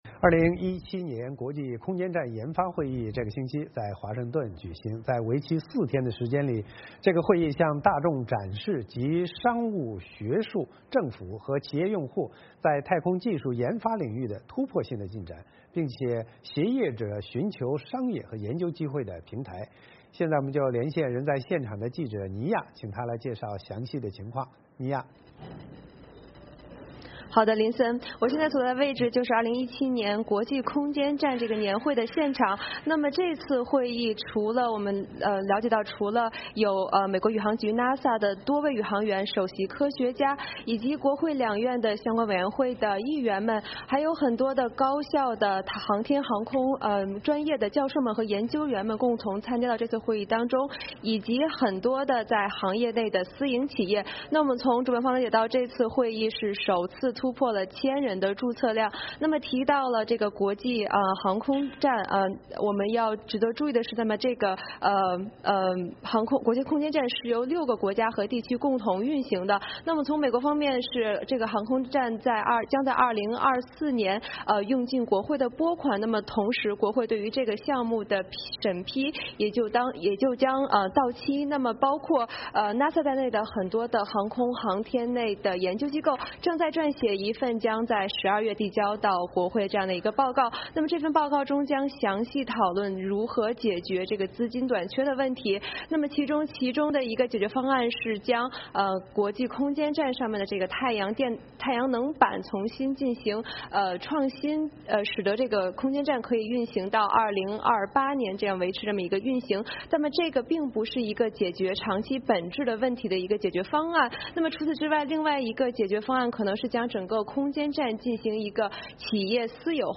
VOA连线：美国国际空间站研发年会 旨推进太空技术